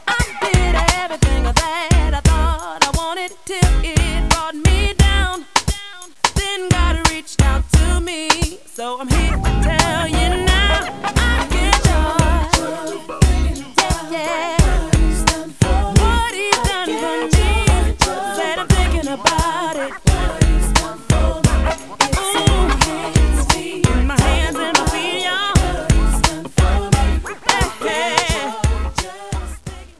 Here are wav-files from famous R&B artists